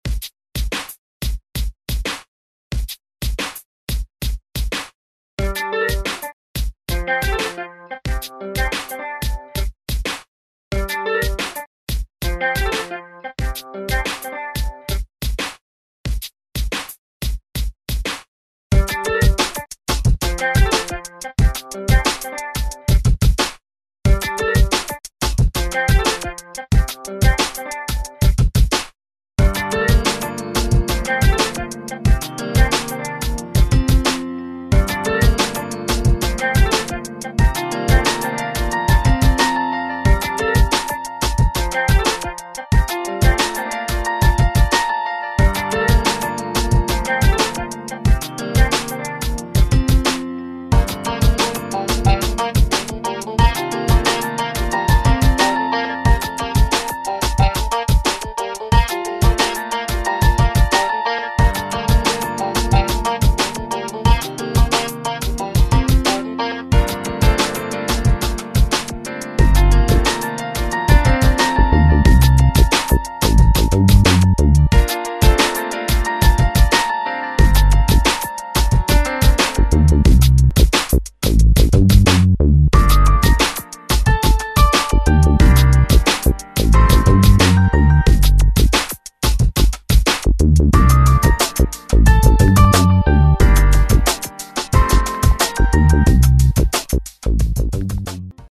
serr (2.25 Mb) самый первый трек и наиболее удачный, концевка немного смазанна( в оригинале была другая, просто оригинал утерян, в связи с крахам HDD)